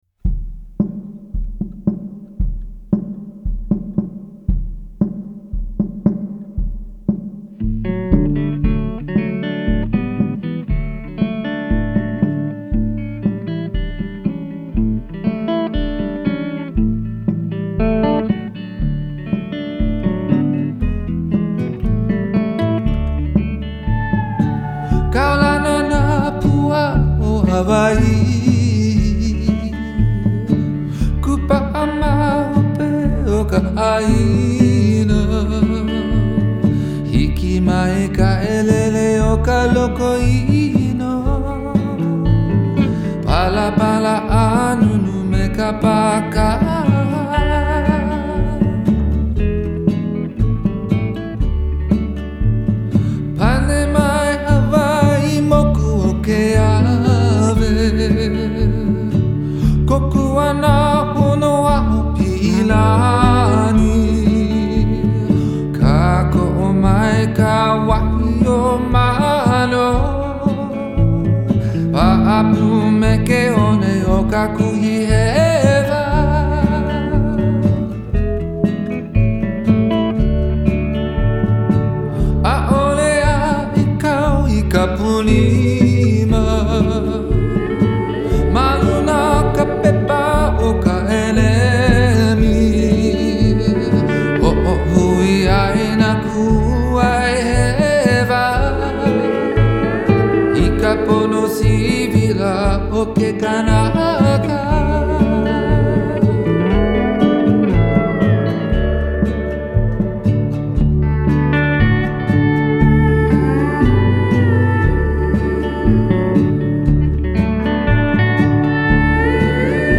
Genre : Folk